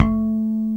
Index of /90_sSampleCDs/Roland LCDP02 Guitar and Bass/BS _Jazz Bass/BS _E.Bass FX